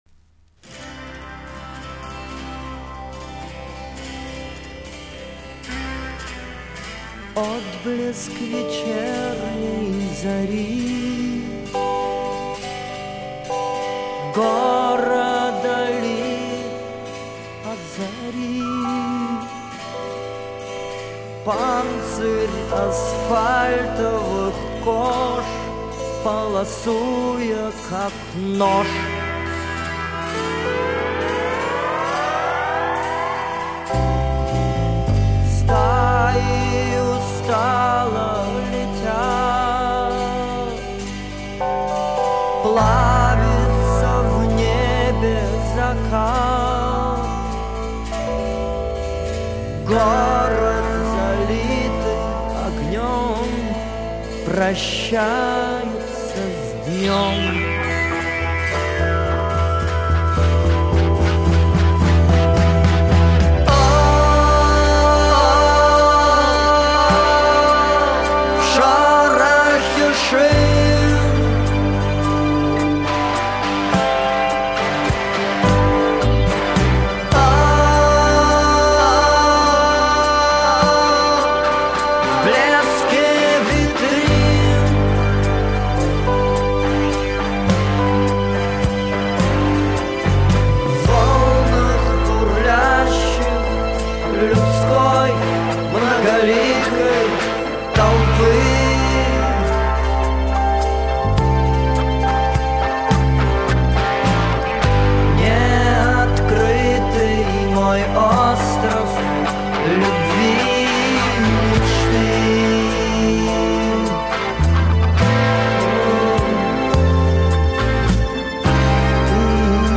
рок-группа